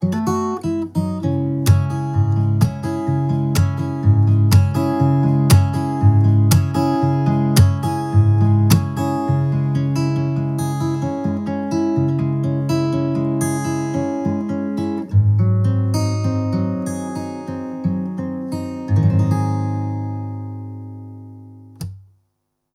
Audio Technica AT-4050 - damski wokal